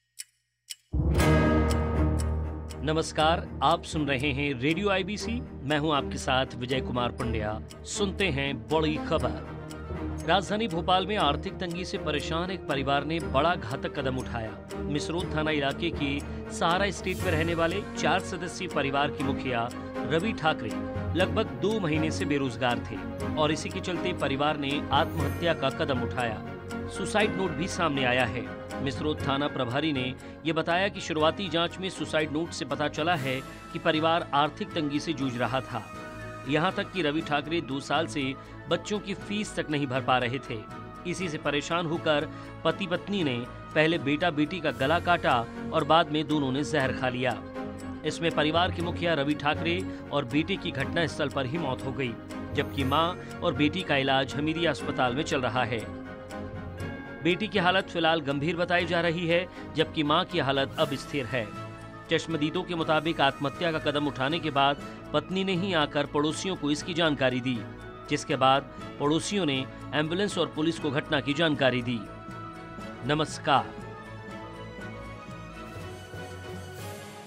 2808-RADIO-BPL-SUICIDE-1.mp3